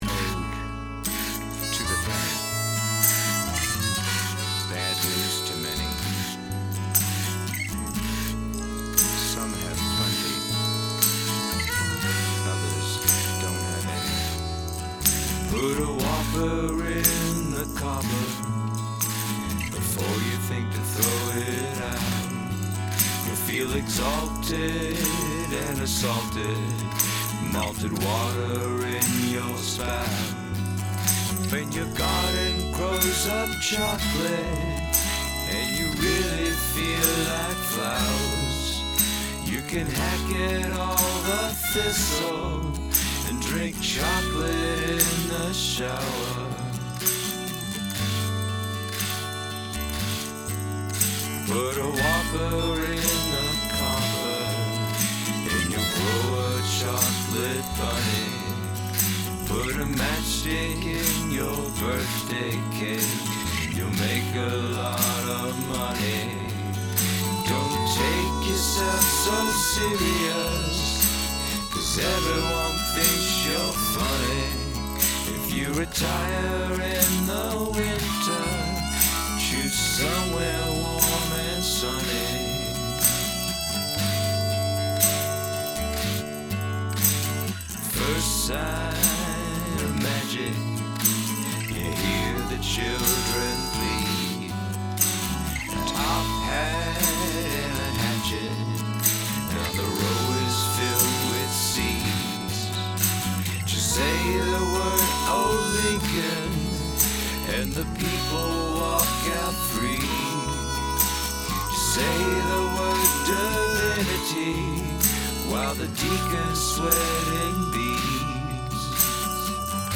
+1 on the creative rhythm backing.
Are you using loose change as your shaker?
I like the harmonica!